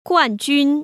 [guànjūn] 꾸안쥔  ▶